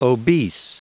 Prononciation du mot obese en anglais (fichier audio)
Prononciation du mot : obese